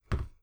Impacts
fruit1.wav